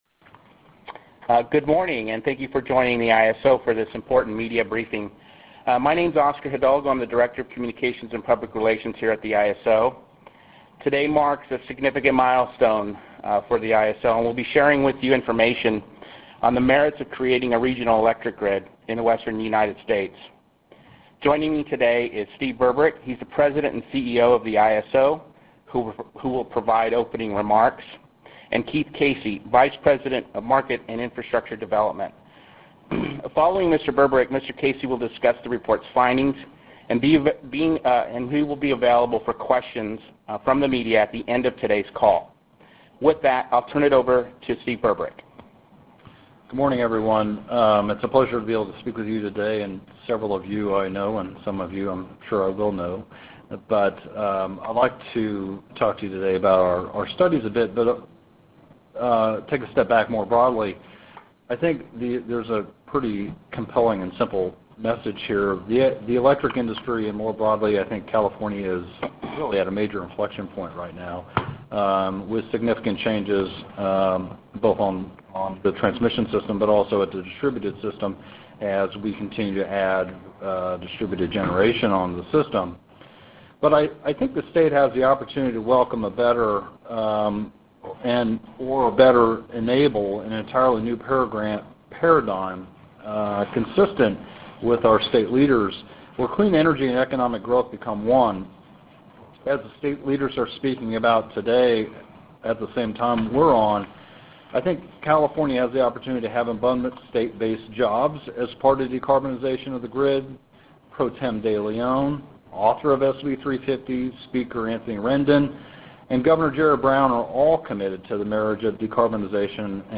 Library | Stakeholder teleconference - Senate Bill 350 final study results - Jul 12, 2016 | California ISO